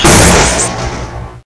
tauShoot.ogg